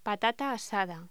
Locución: Patata asada
voz